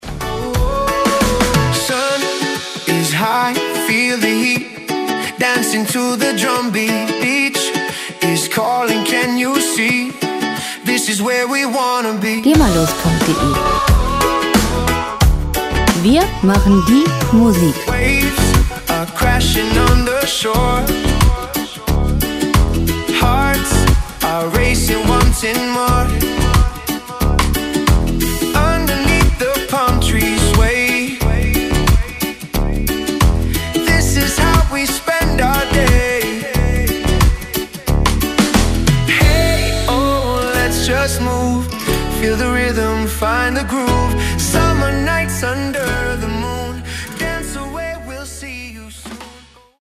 • Reggae-Pop